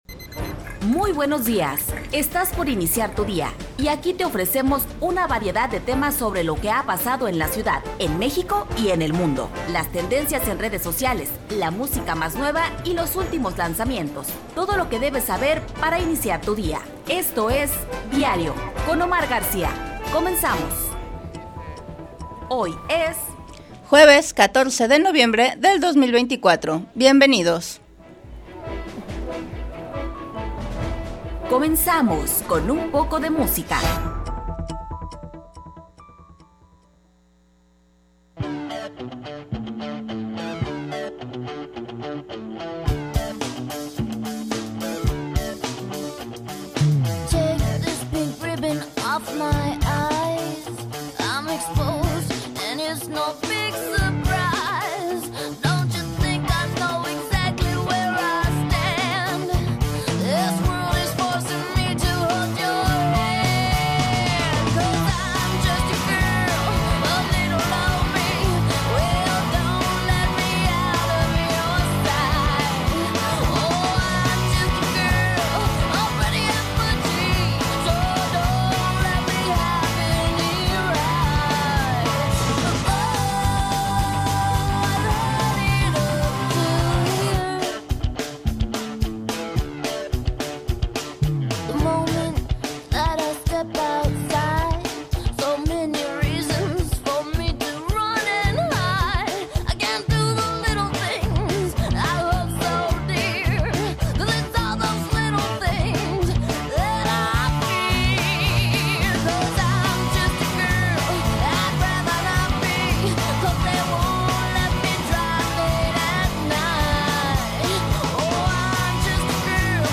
Revista Informativa de Radio Universidad de Guadalajara